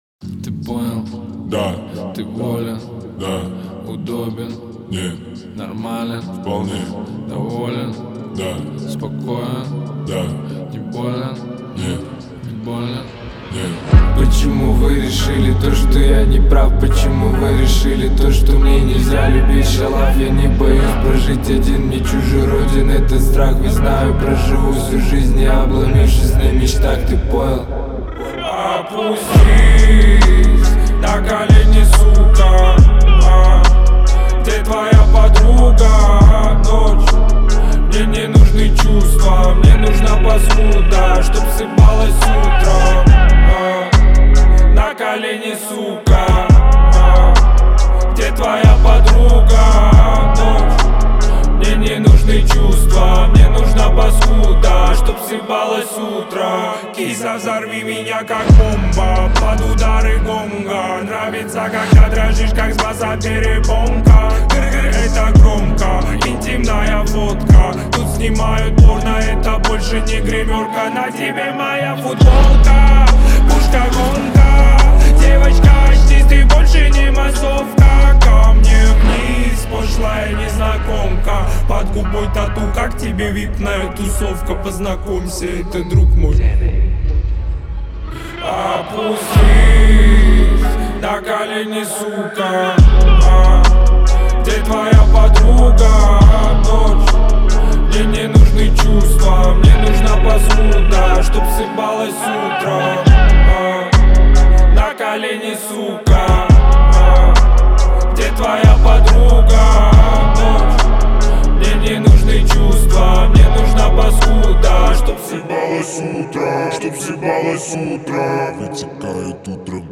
это мощный рок-трек